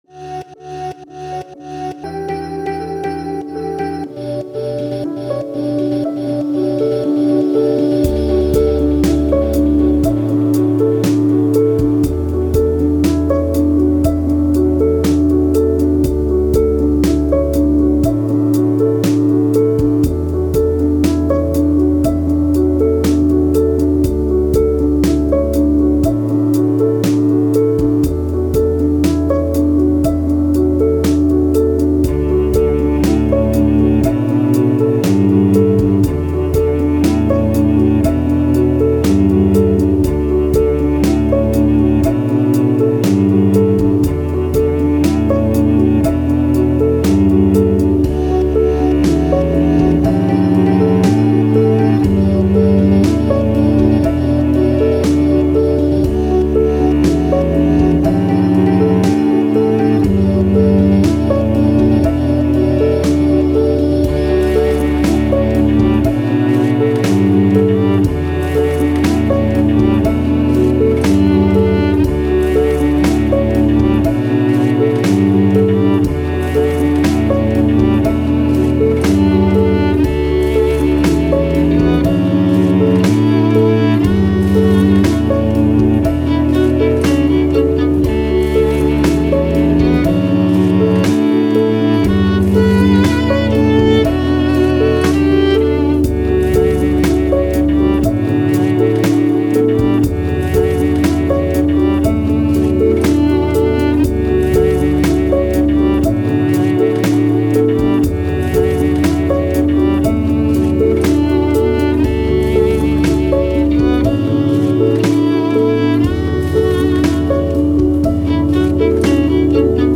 Ambient, Downtempo, Soundtrack, Emotive, Strings, Hope